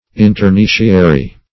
Search Result for " interneciary" : The Collaborative International Dictionary of English v.0.48: Interneciary \In`ter*ne"cia*ry\, Internecinal \In`ter*ne"ci*nal\, a. Internecine.